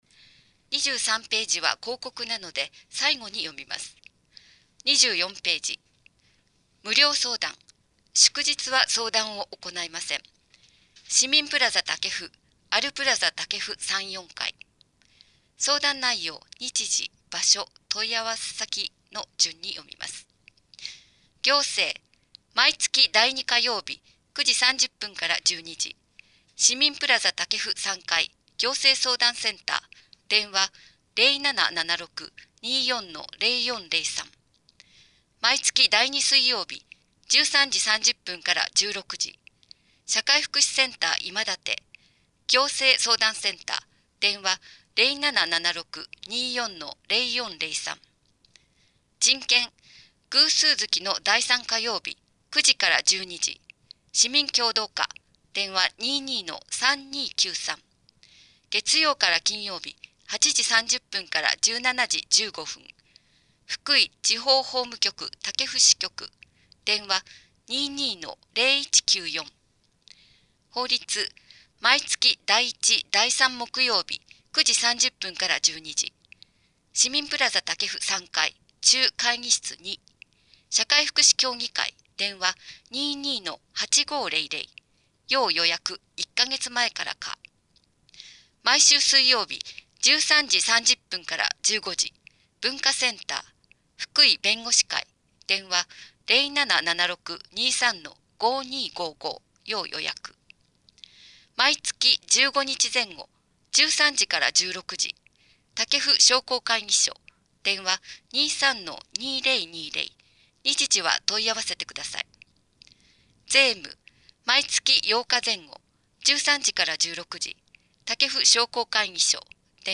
※越前市広報の音訳は音訳ボランティア「きくの会」の皆さんのご協力のもと配信しています。